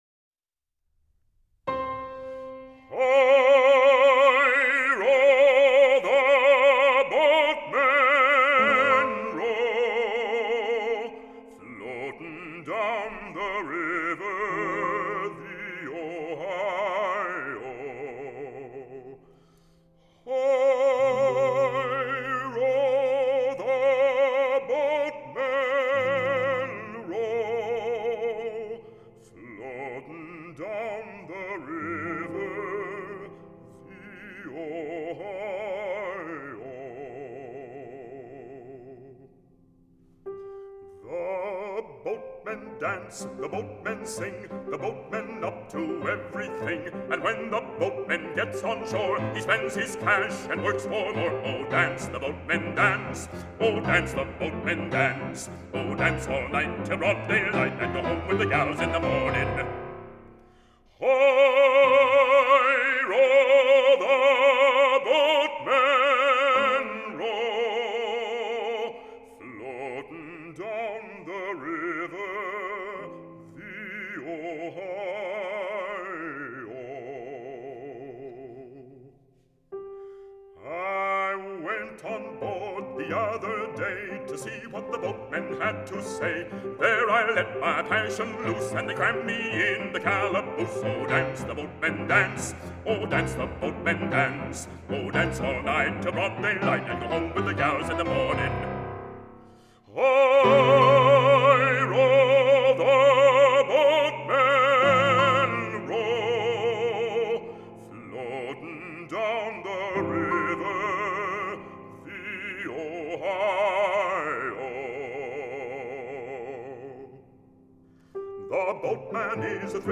Música vocal
Canto